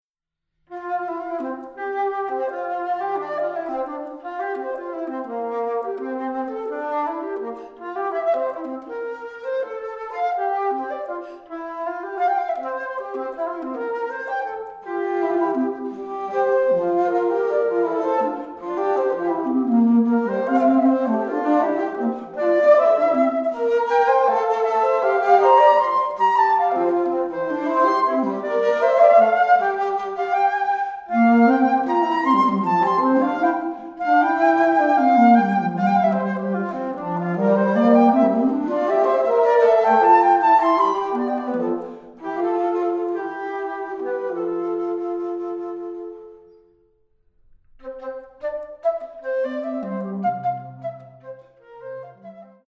Australian classical music
low flutes
piano, alto flute and piccolo
Expressive and relaxed